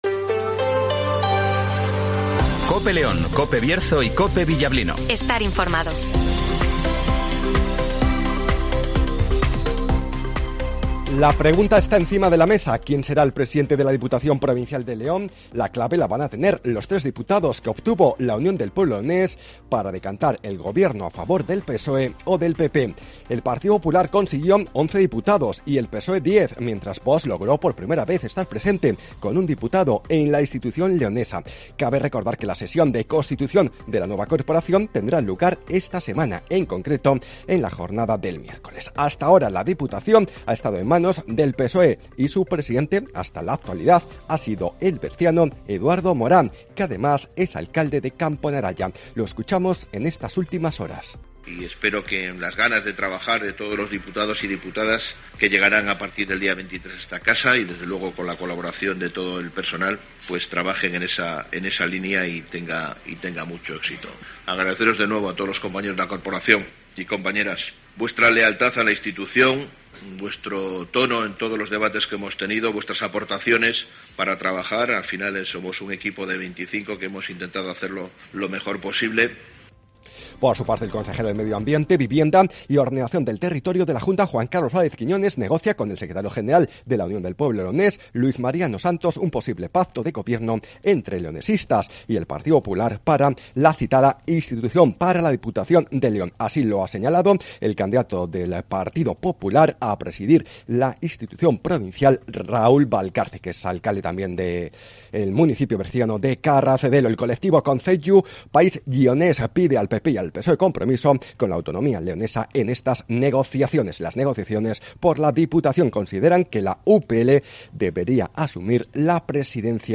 Redacción digital Madrid - Publicado el 21 ago 2023, 08:24 - Actualizado 22 ago 2023, 08:42 1 min lectura Descargar Facebook Twitter Whatsapp Telegram Enviar por email Copiar enlace - Informativo Matinal 08:24 h